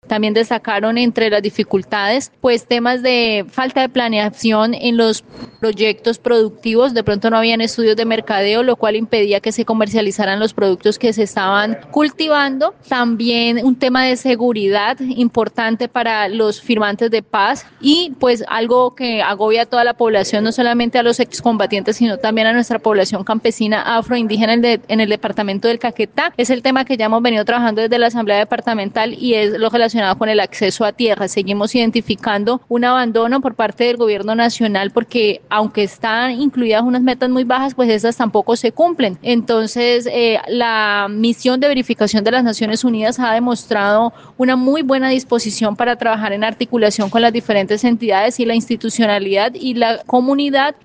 La diputada Liberal, Viviana Silva Saldaña, dijo sobre el tema que, estos obstáculos no solo afectan la economía de los firmantes de paz, también generan inestabilidad y vulnerabilidad en la región.
En medio de una sesión especial, al interior de la asamblea departamental, la misión de observación al proceso de paz en Colombia, también mencionó que la violencia es otro tema que preocupa, ya que han perdido la vida varios firmantes de paz en situaciones violentas.